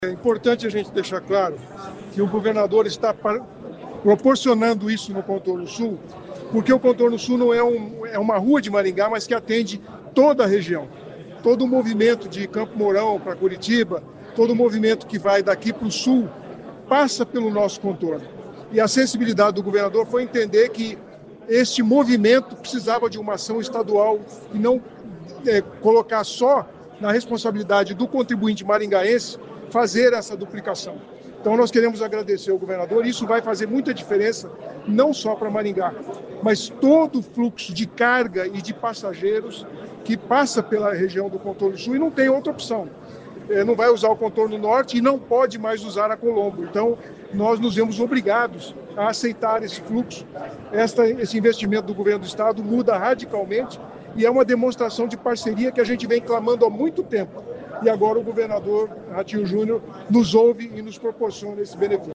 O prefeito Sílvio Barros disse que a duplicação do Contorno Sul é uma das maiores obras do Estado do Paraná.